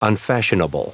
Prononciation du mot : unfashionable